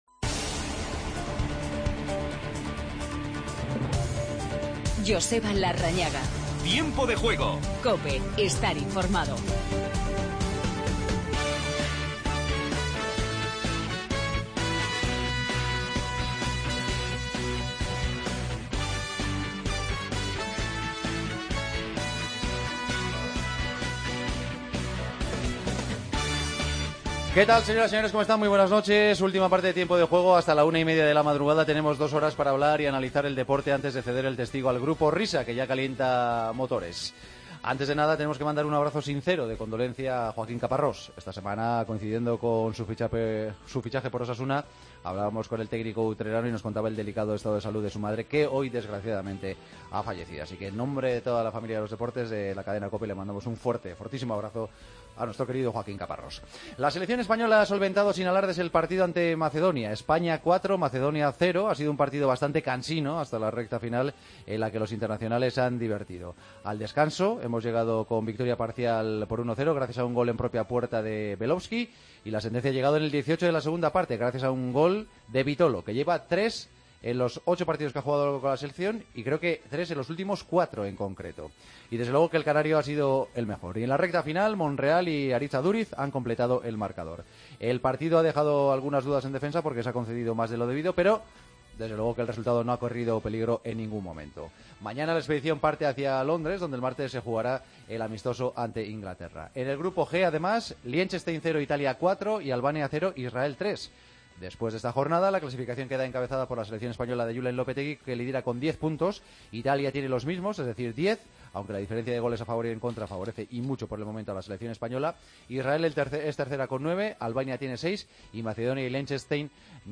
España cumple el expediente ante Macedonia y sigue líder. Griezmann está convencido que estará disponible ante el Real Madrid. Escuchamos a Julen Lopetegui. Entrevistas Vitolo y a Monreal.